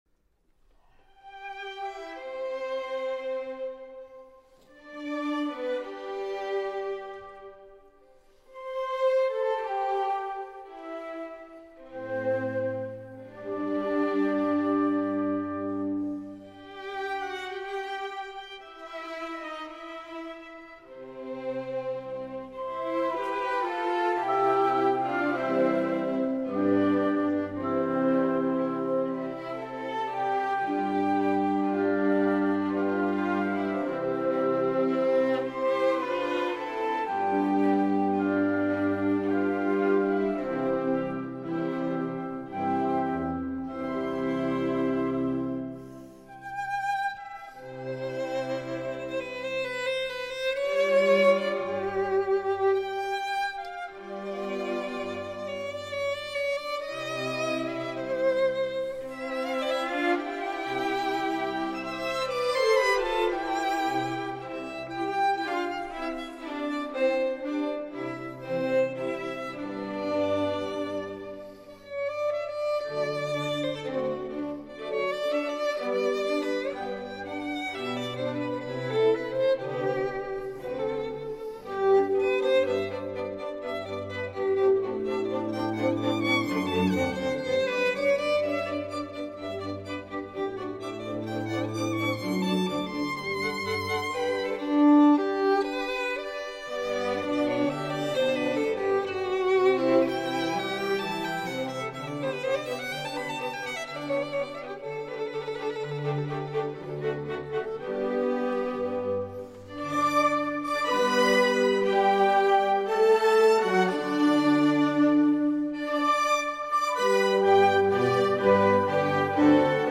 slow movement